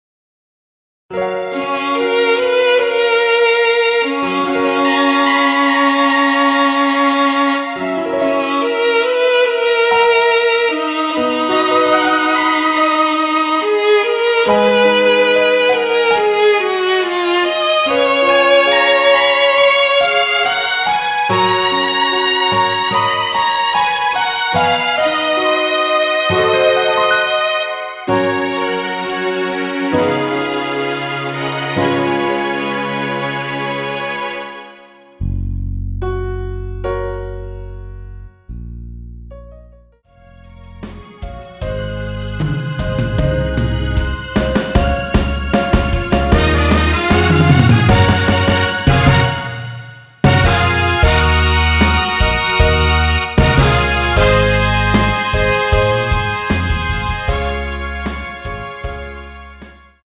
원키에서(+5)올린 MR입니다.
F#
앞부분30초, 뒷부분30초씩 편집해서 올려 드리고 있습니다.
중간에 음이 끈어지고 다시 나오는 이유는